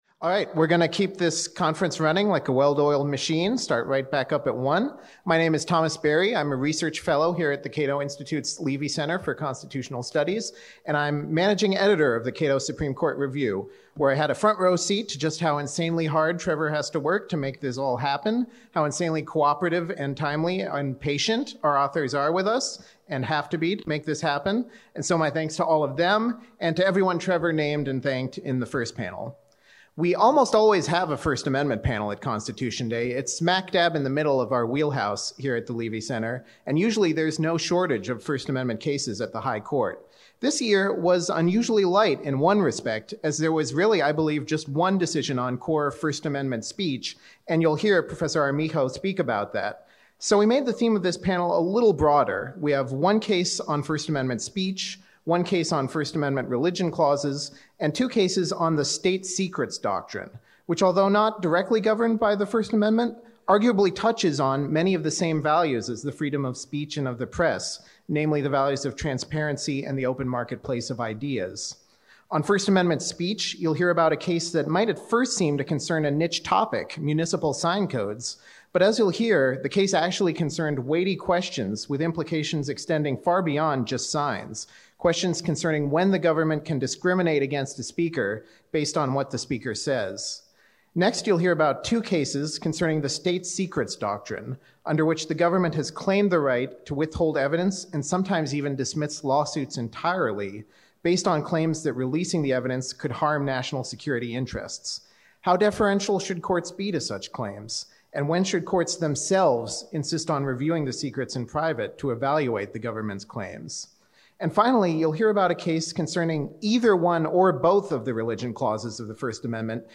Cato’s annual Constitution Day symposium marks the day in 1787 that the Constitutional Convention finished drafting the U.S. Constitution. We celebrate that event each year with the release of the new issue of the Cato Supreme Court Review and with a day‐long symposium featuring noted scholars discussing the recently concluded Supreme Court term and the important cases coming up.